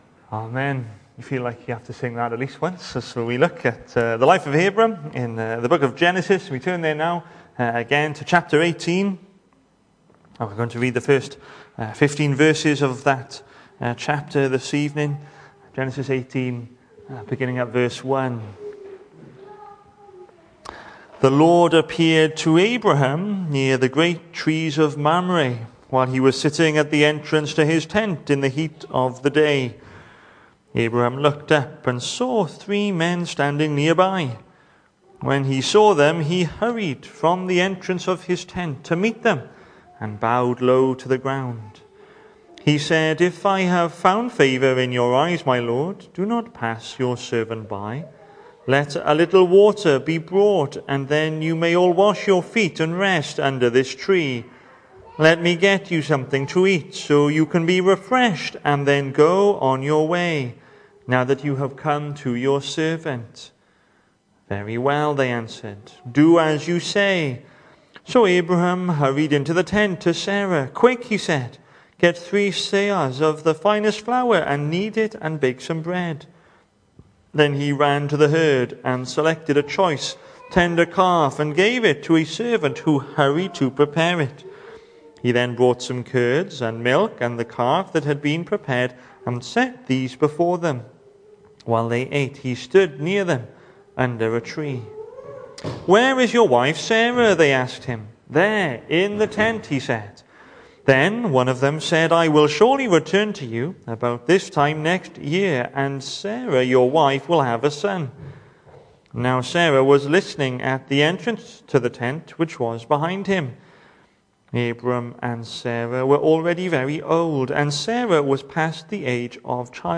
The 9th of November saw us hold our evening service from the building, with a livestream available via Facebook.